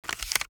ORGANIC Paper Book Page Turn Short 02.wav